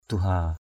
/d̪u-ha:/ (d.) giờ cầu nguyện = heure de la prière. prayer hours.